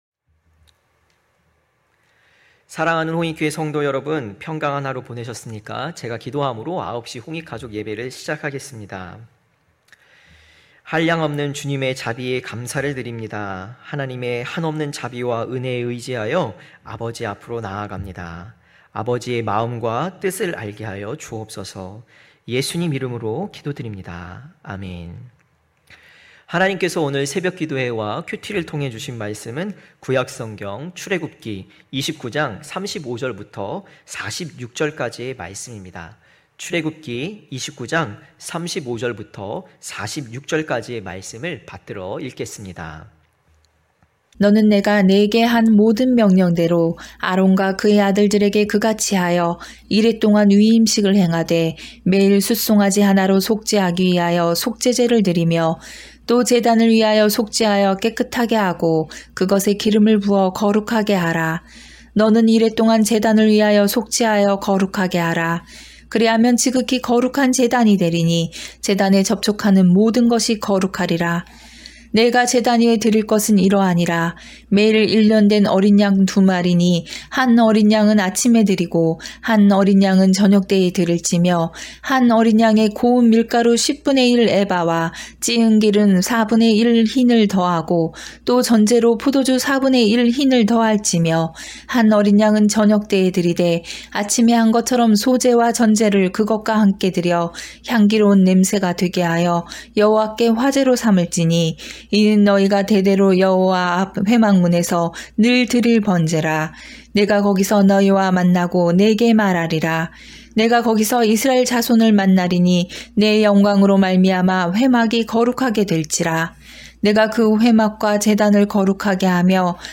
9시홍익가족예배(10월11일).mp3